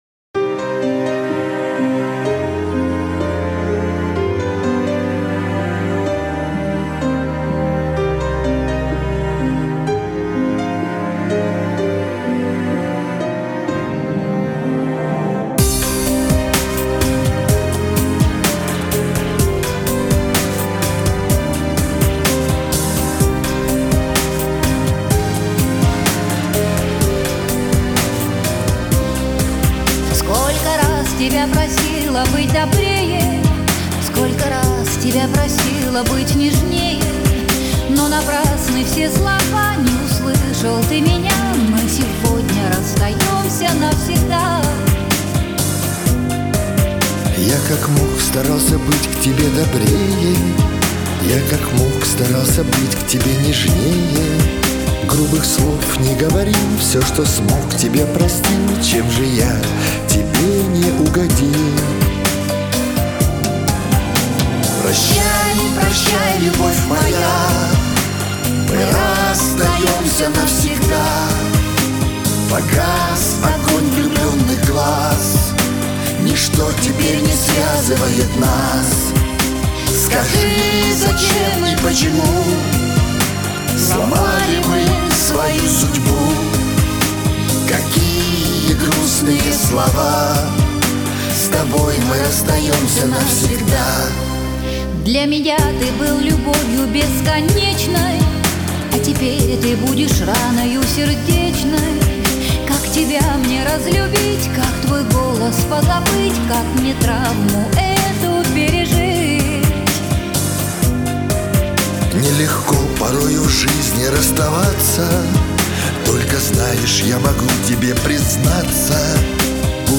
Дуэты
Жанр: Рор